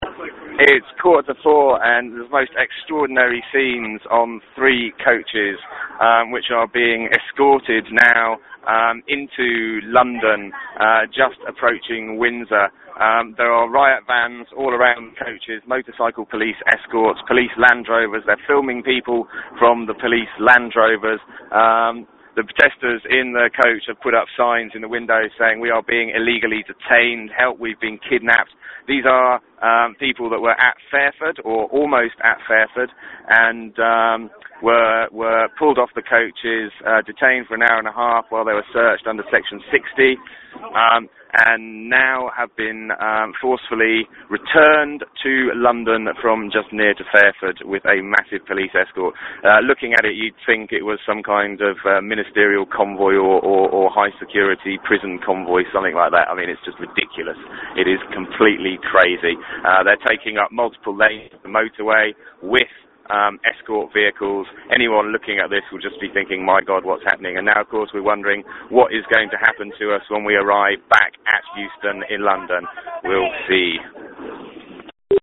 Audio report: 3 London coaches returned at Fairford, escorted back to London